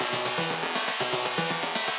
SaS_Arp04_120-C.wav